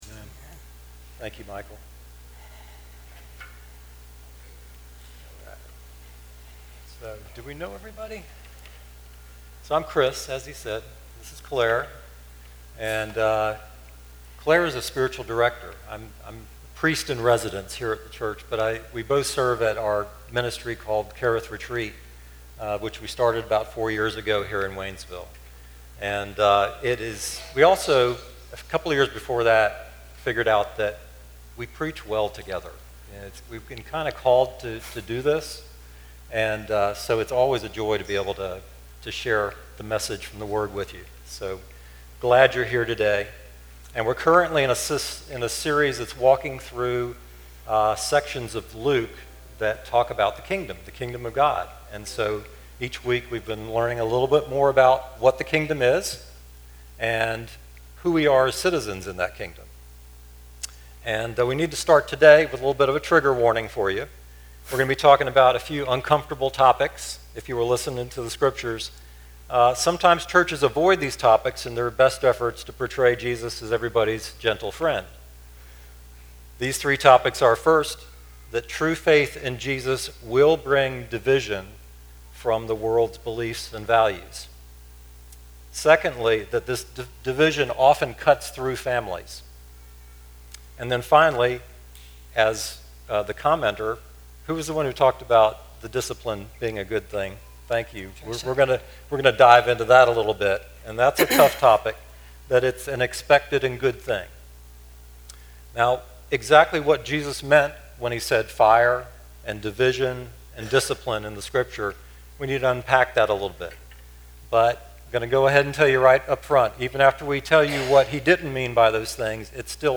message from Sunday, August 17, 2025.